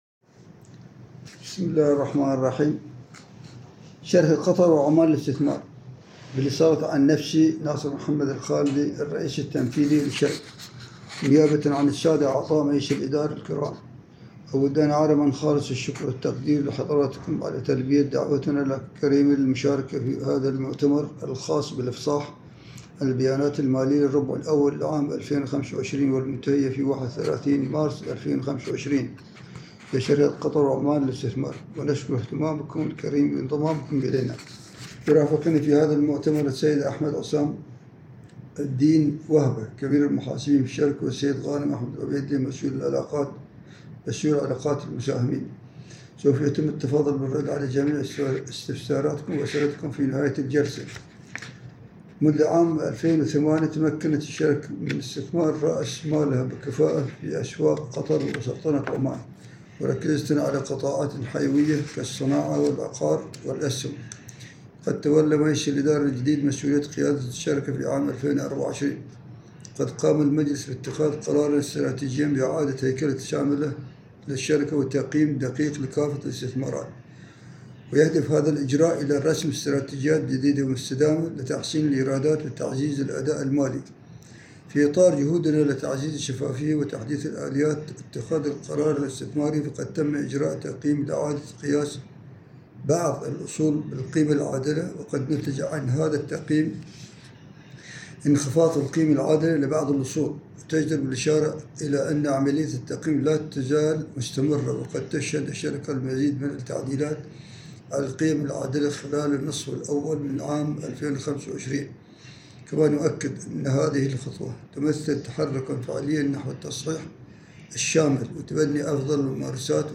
Conference Call - 1st Quarter